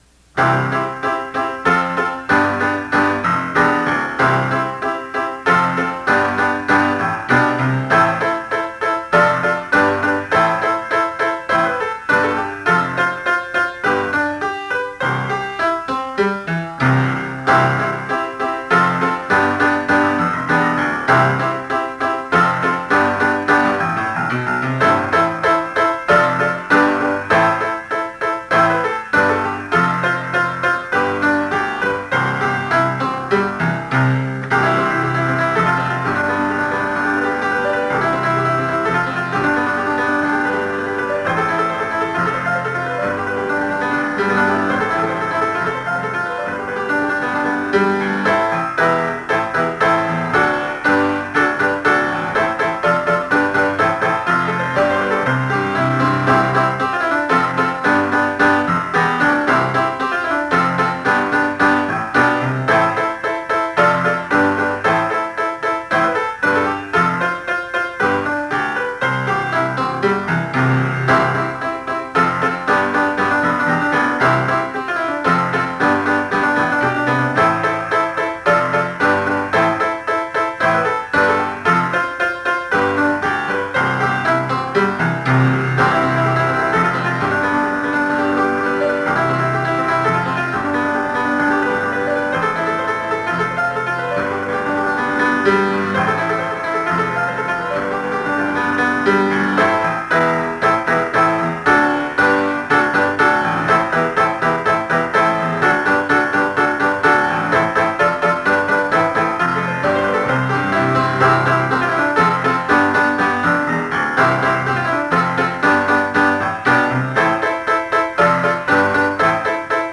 The following pieces consist of MIDI and/or MP3 files for the piano.
It was hard to get the midi to sound correct (I entered it by hand) but my live (MP3) recordings have mistakes - so you have a choice of accurate and uninspired, or inaccurate but at least not flat.